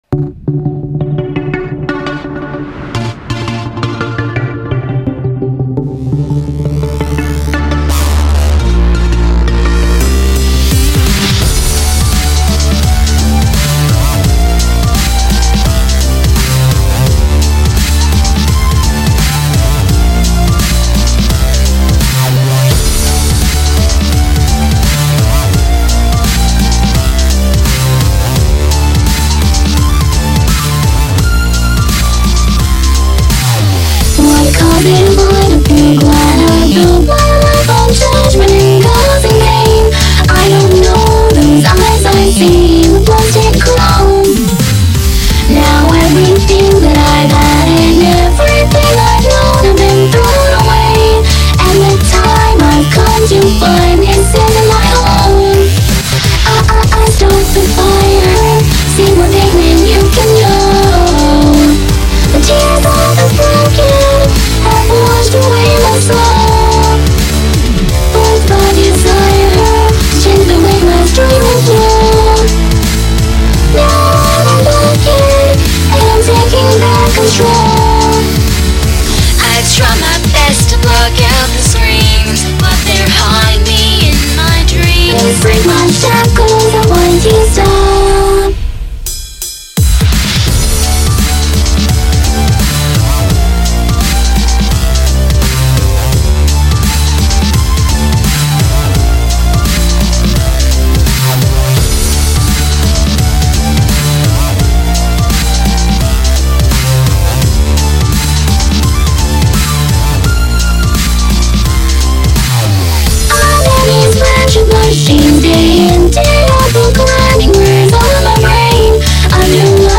I OWN NOTHING BUT MY VOICE!